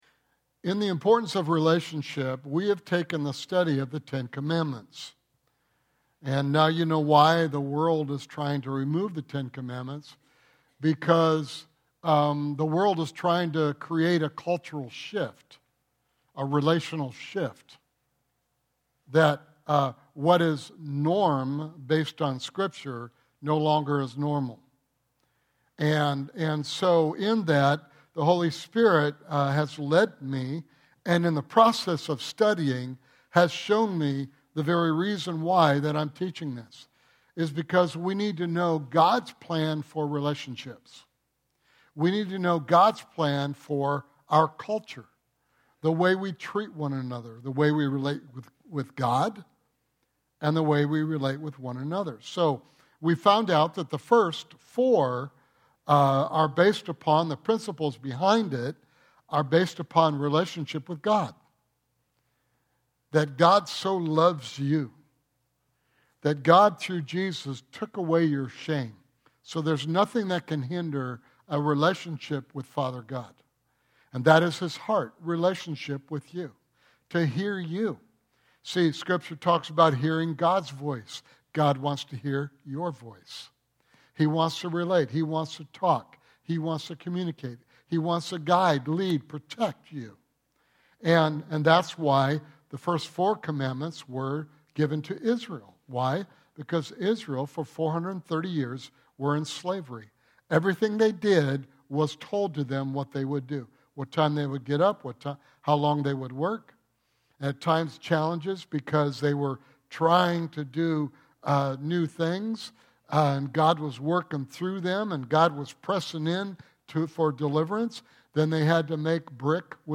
The Importance of Relationship Sunday morning sermon